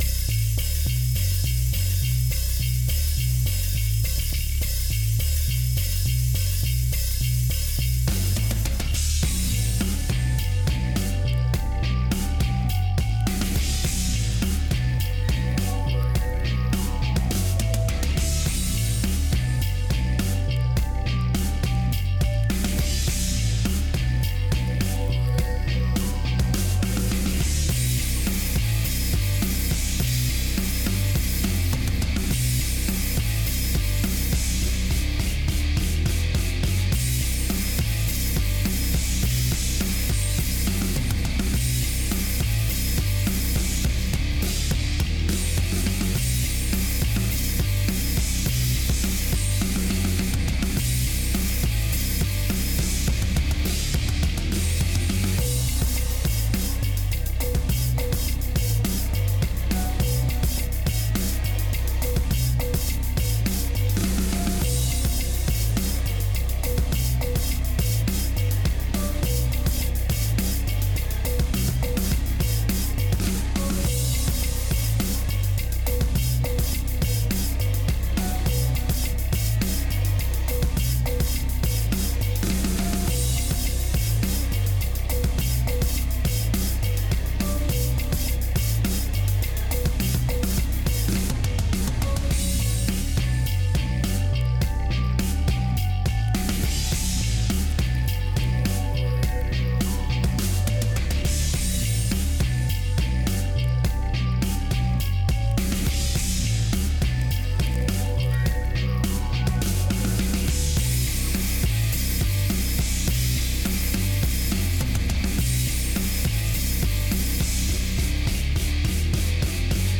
ambience